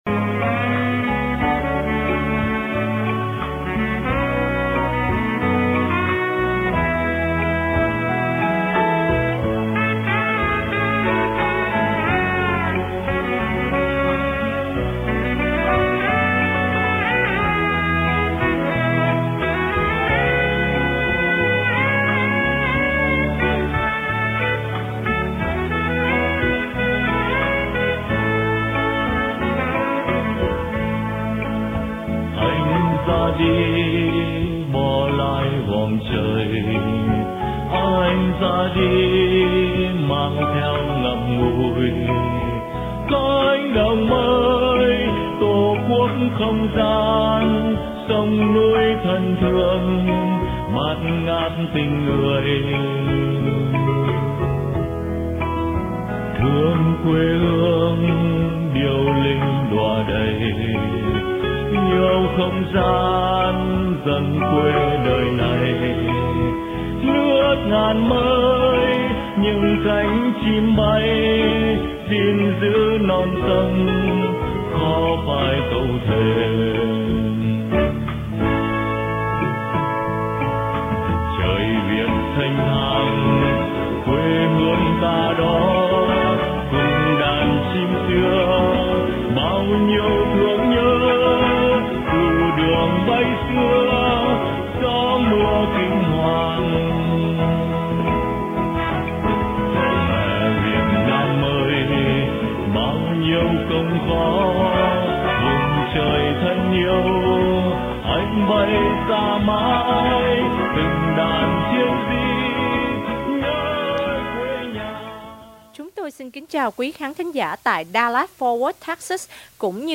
Từ Cánh Đồng Mây- Phỏng Vấn